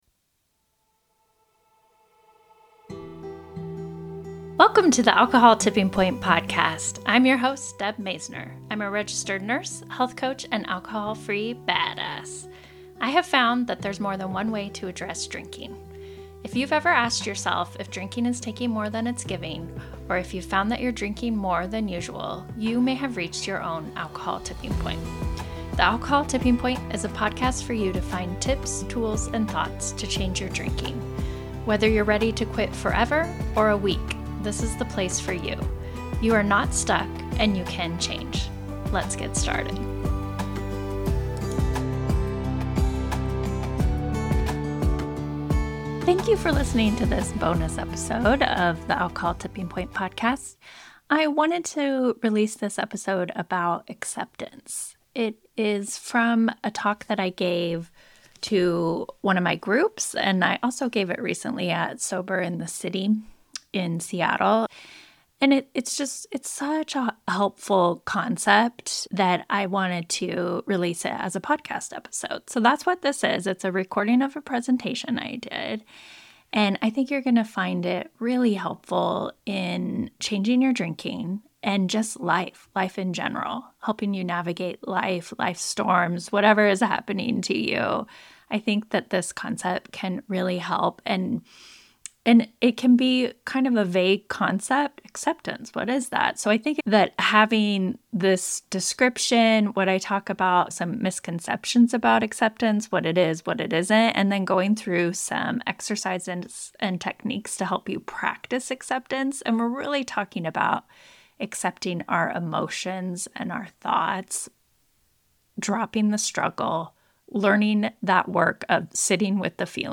In this episode, I share a presentation on the powerful concept of acceptance. Acceptance means embracing the present moment and our emotions as they are, without trying to alter them.